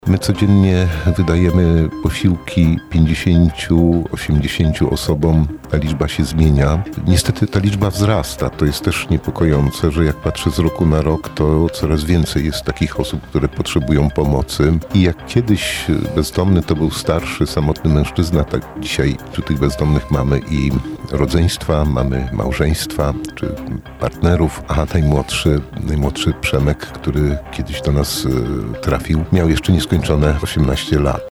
[PORANNA ROZMOWA]